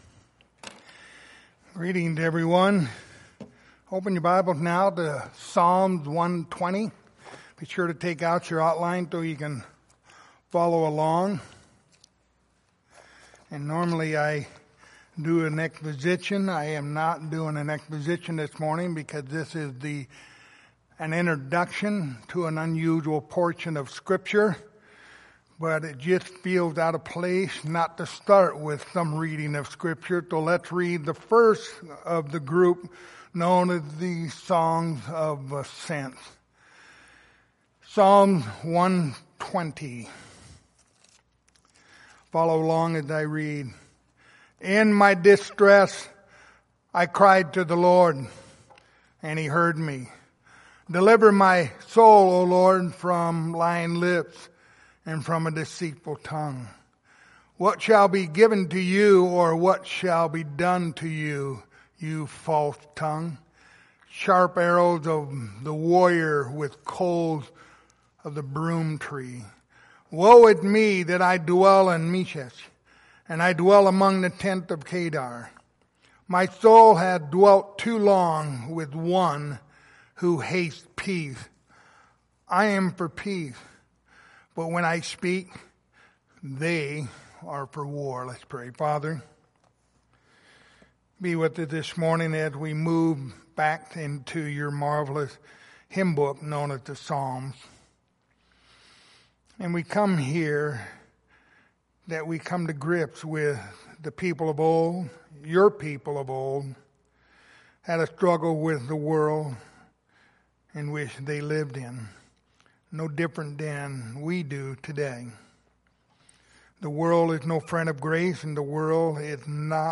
The book of Psalms Passage: Psalm 120-134 Service Type: Sunday Morning Topics